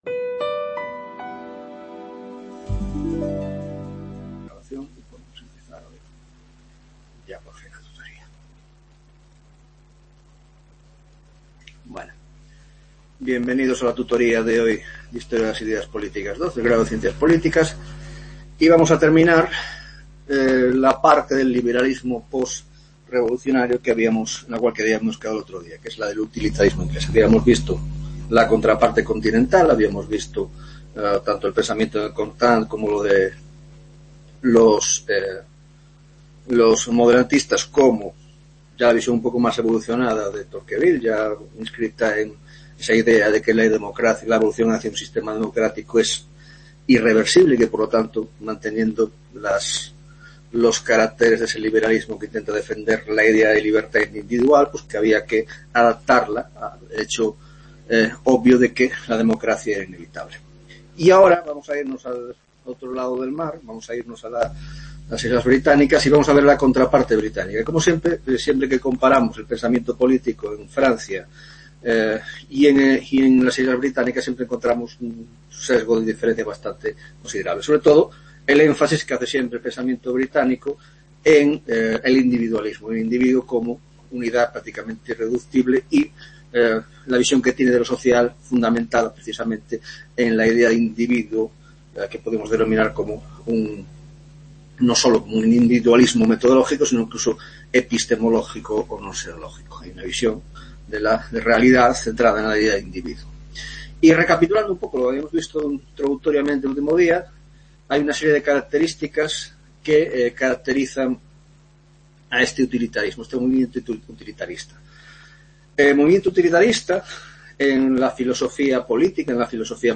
10ª Tutoria de Historia de las Ideas Políticas 2 (Grado de Ciencias Políticas) - Utilitarismo Ingles